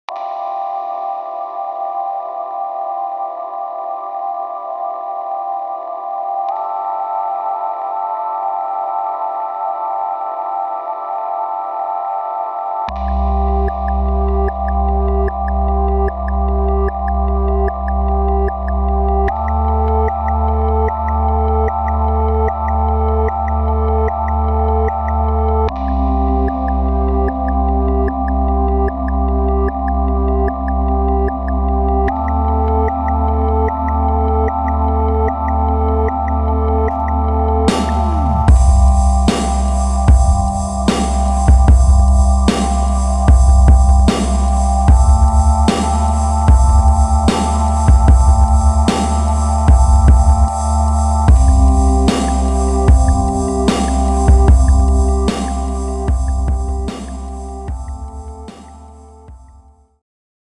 Downtempo